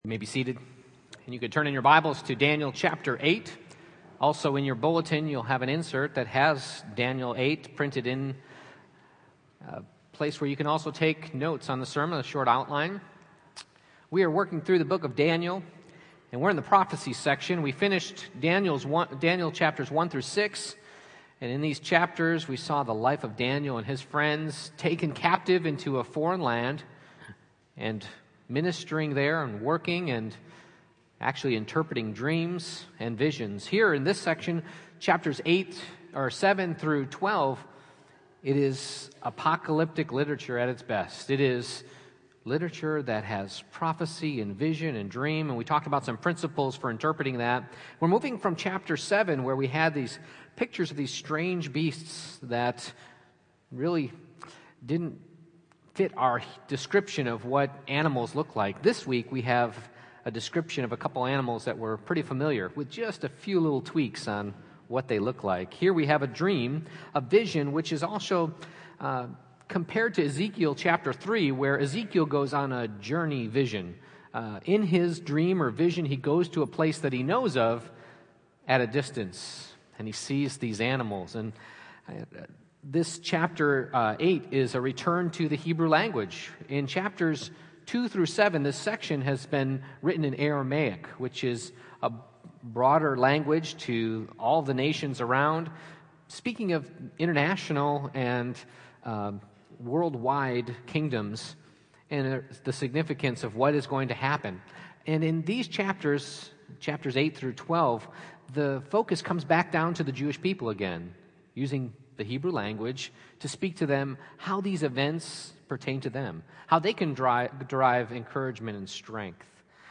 Daniel 8:1-27 Service Type: Morning Worship « Beastly Visions